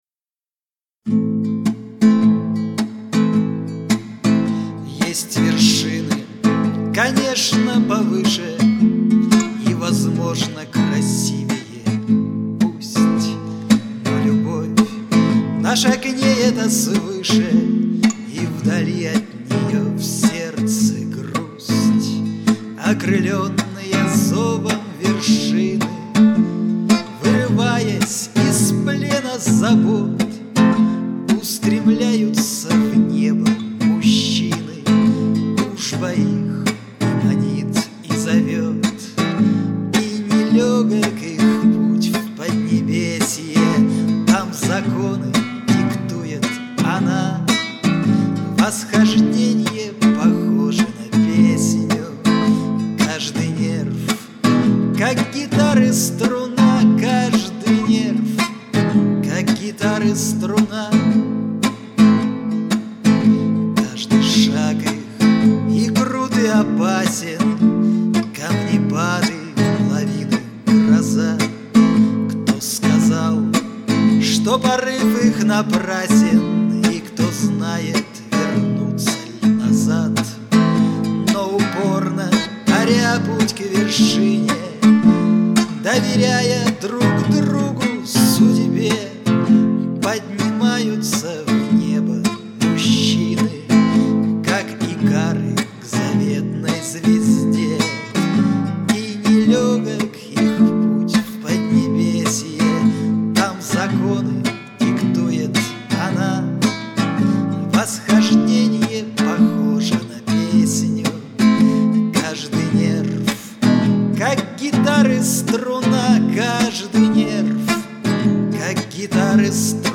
Любителям авторской песни.